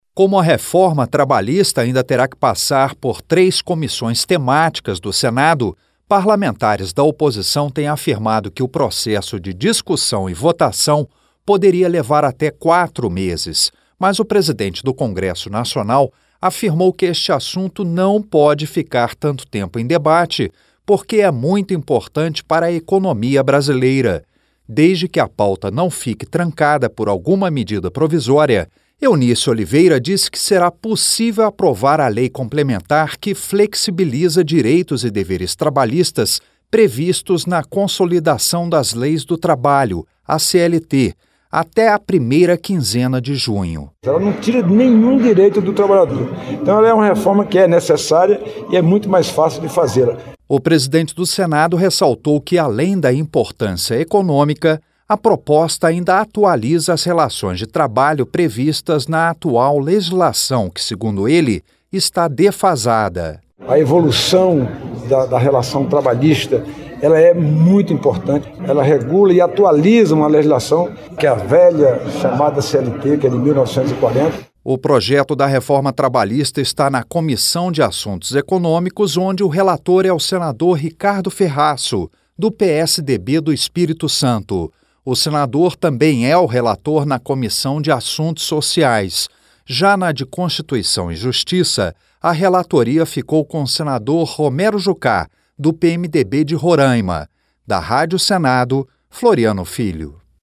Em declaração nesta terça-feira (16), o presidente do Senado, Eunício Oliveira afirmou que os trabalhadores não vão perder direitos em caso de aprovação da reforma trabalhista (PLC 38/2017) que está em discussão na Casa. Eunício acha possível votar a proposta até a primeira quinzena de junho.